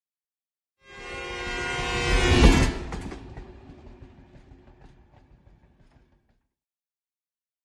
Train Whistle By European 4 - L to R